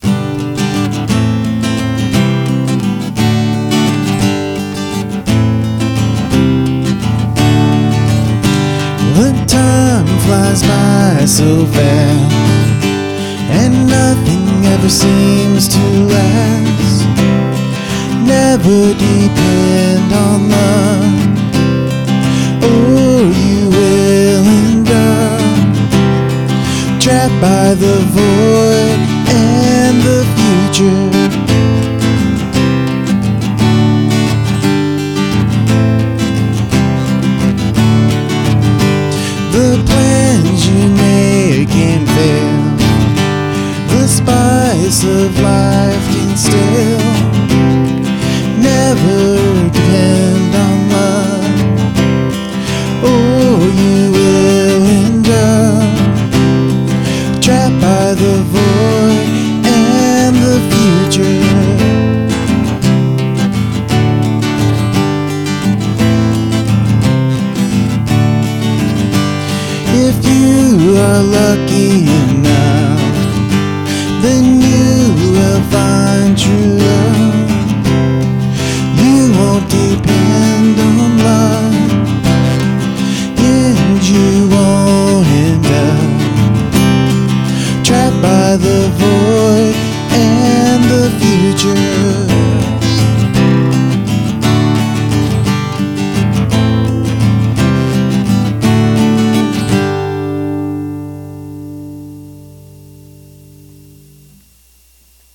Mix sounds perfect!
Great man and guitar tune!